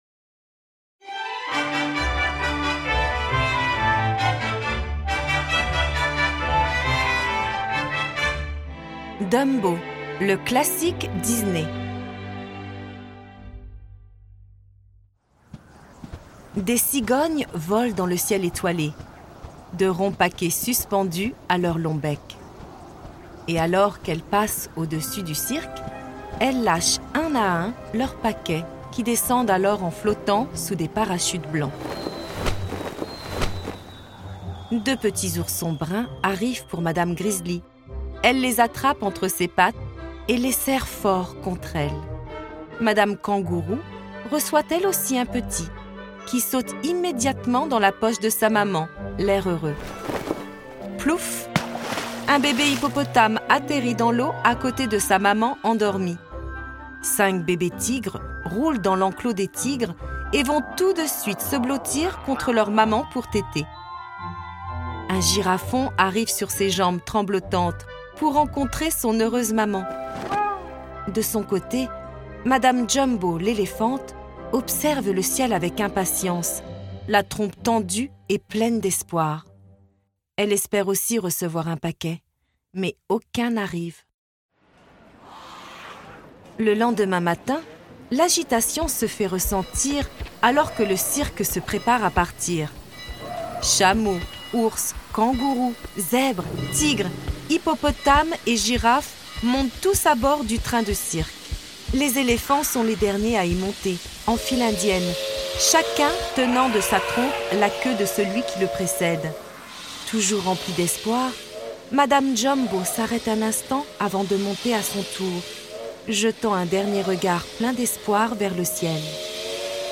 01 - Chapitre 01_ Dumbo - L'histoire à écouter_ Dumbo.flac